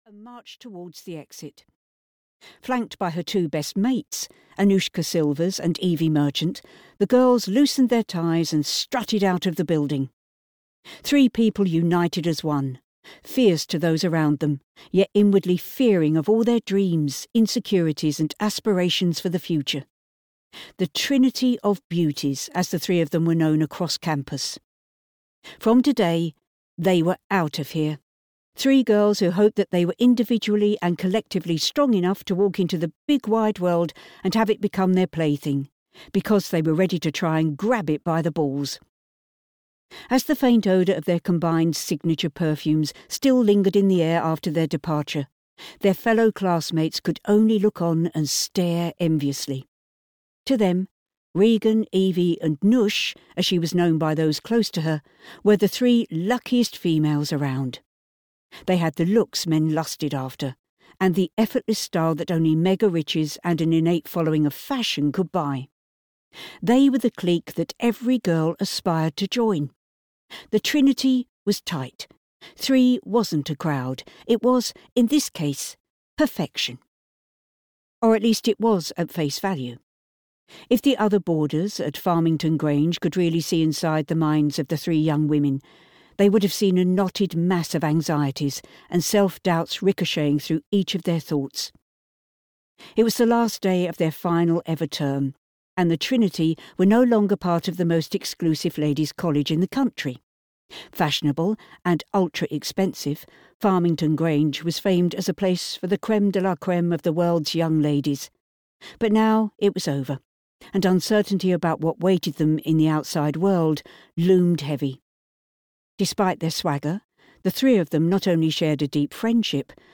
Audio knihaTrinity (EN)
Ukázka z knihy